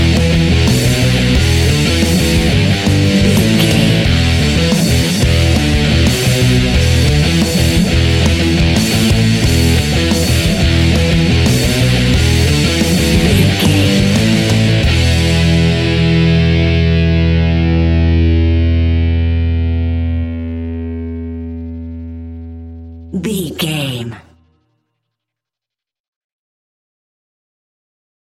Hard and Powerful Metal Rock Music Cue 15 Sec.
Epic / Action
Aeolian/Minor
hard rock
heavy metal
instrumentals
Rock Bass
heavy drums
distorted guitars
hammond organ